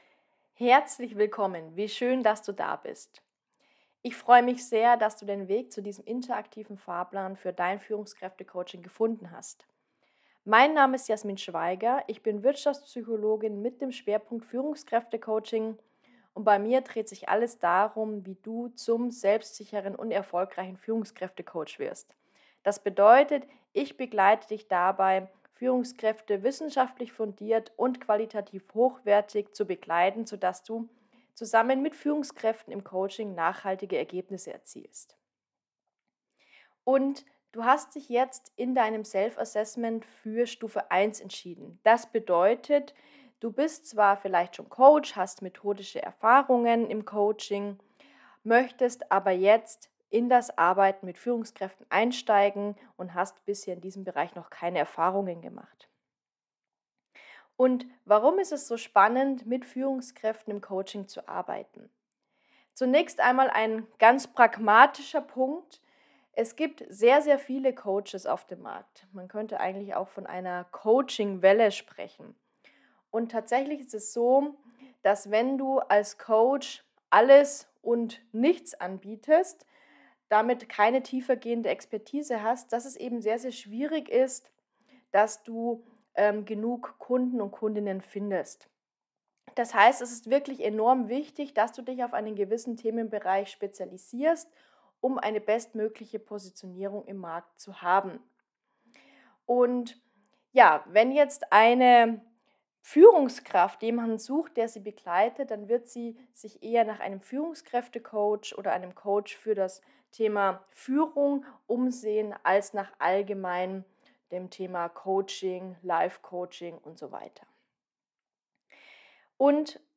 Meine Audionachricht für dich